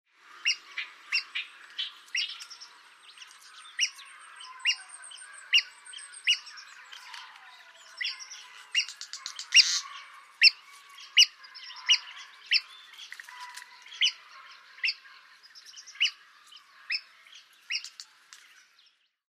Tachuris rubrigastra
sieteColores-Tachuris-rubrigastra.mp3